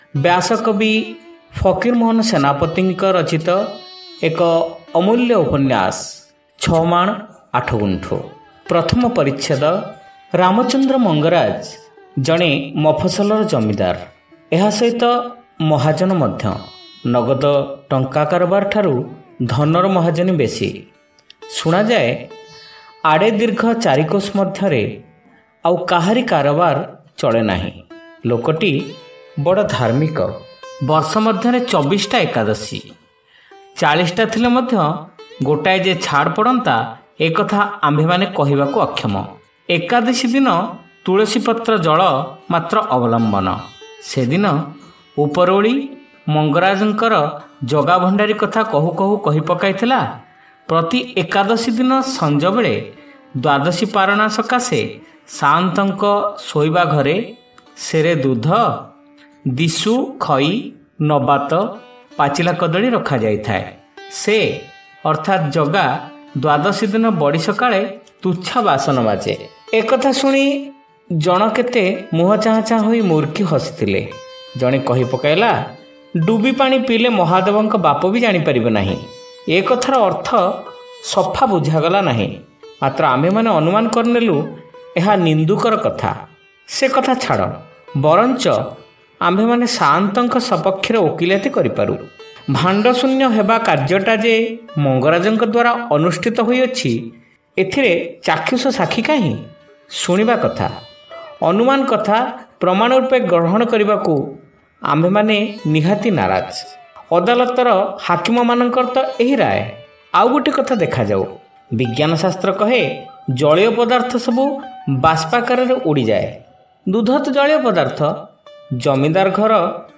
ଶ୍ରାବ୍ୟ ଉପନ୍ୟାସ : ଛମାଣ ଆଠଗୁଣ୍ଠ (ପ୍ରଥମ ଭାଗ)